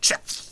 spearman_attack7.wav